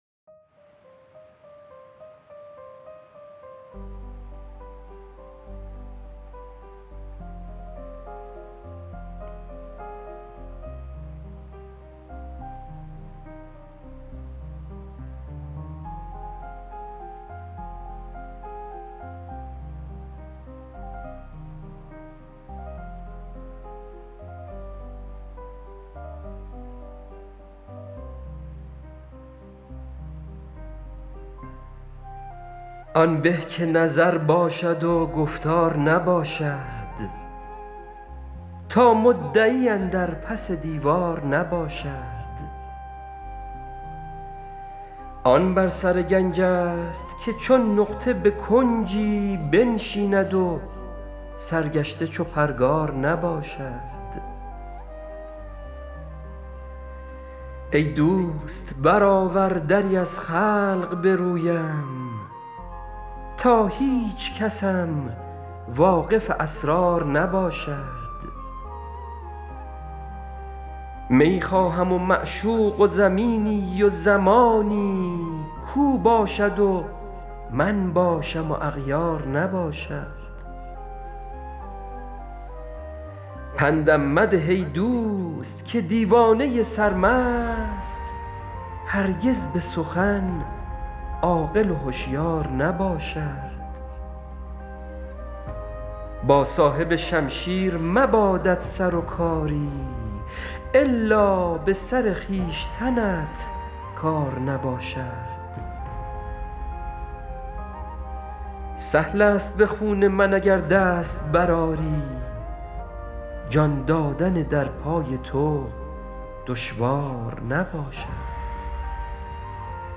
غزل شمارهٔ ۲۰۱ به خوانش